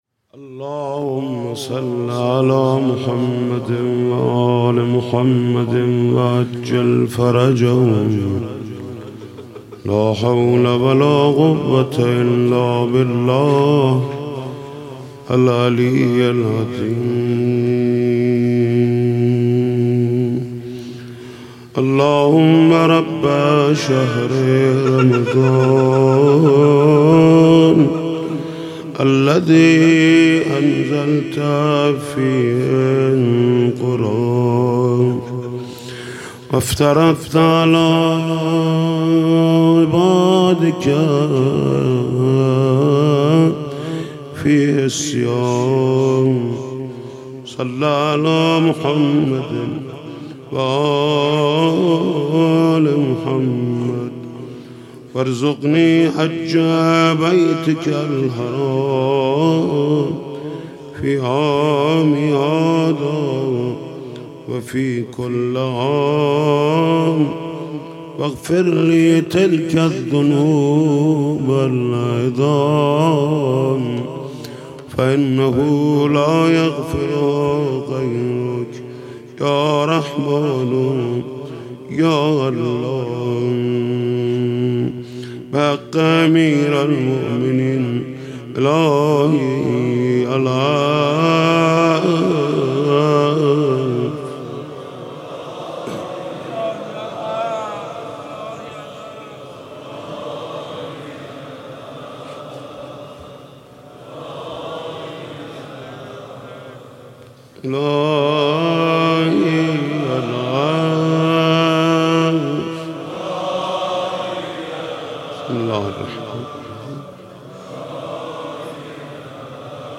«شب بیستم» قرائت فرازی از دعای ابوحمزه ثمالی